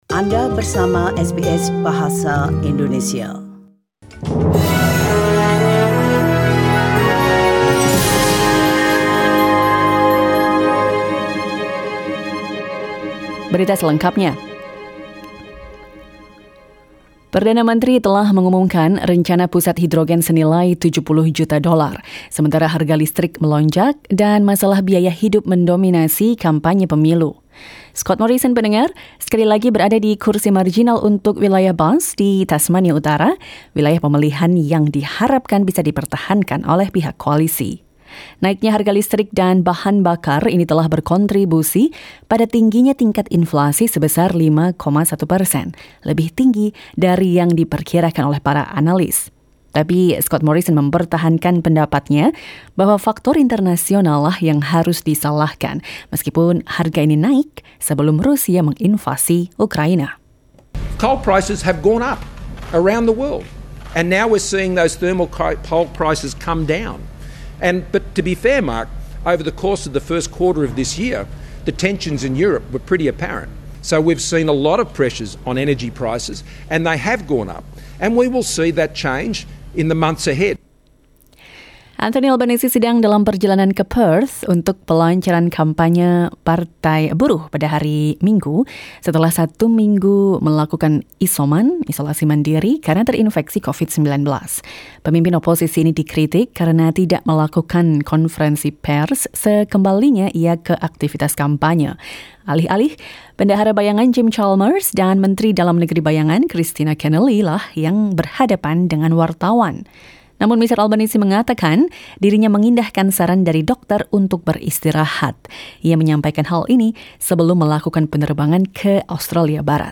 SBS Radio news in Indonesian - 29 April 2022